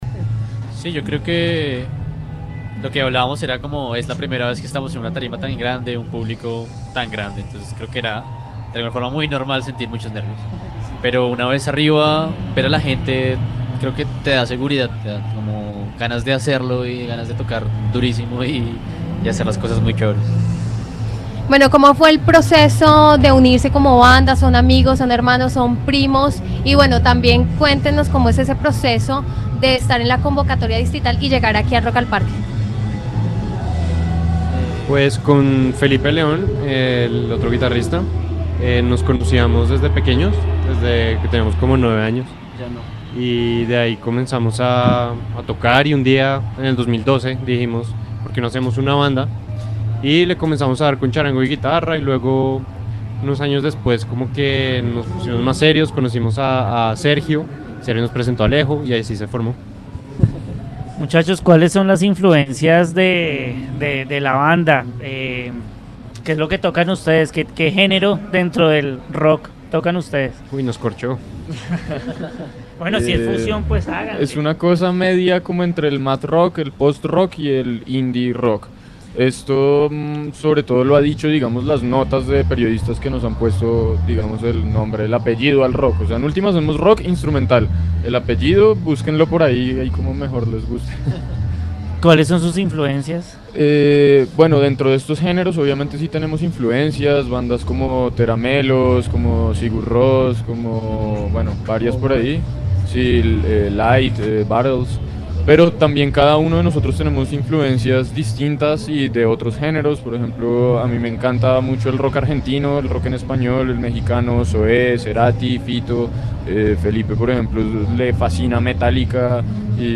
En UNIMINUTO Radio estuvo “Montaña”, una banda que se presenta por primera vez en Rock al Parque.